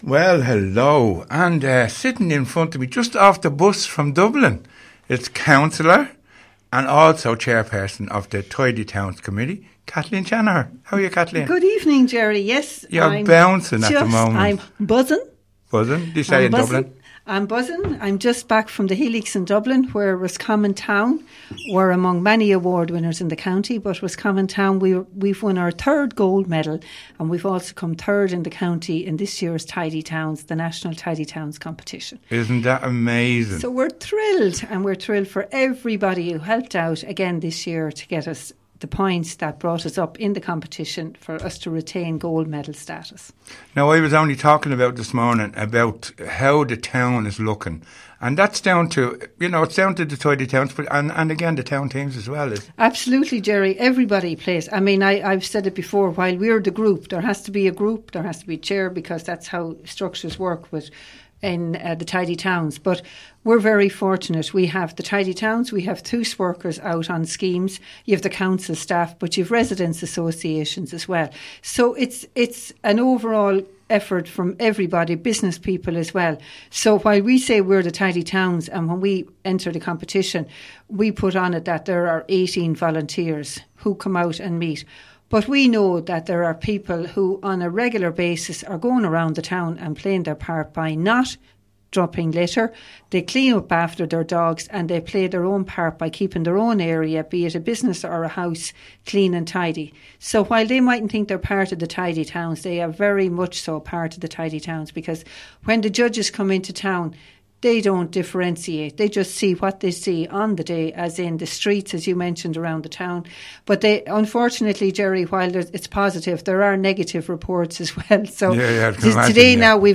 Cllr. Kathleen Shanagher Interview - RosFM 94.6